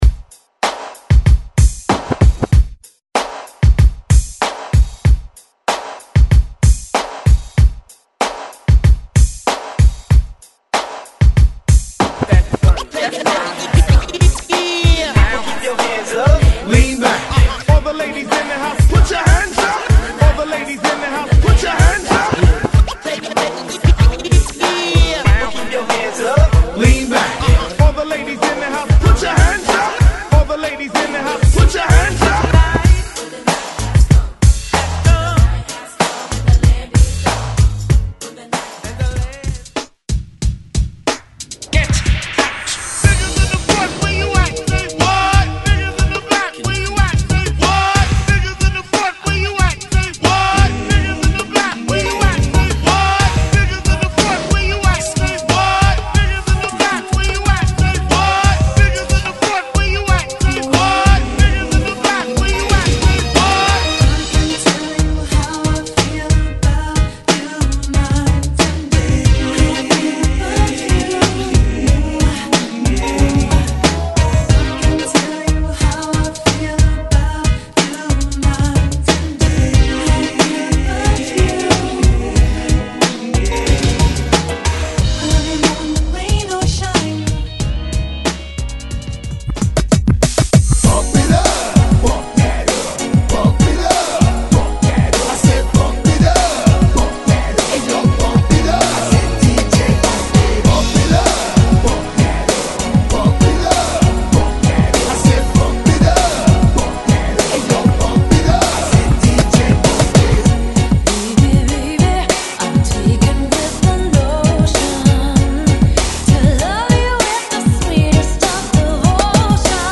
98 Bpm Genre: 90's Version: Clean BPM: 98 Time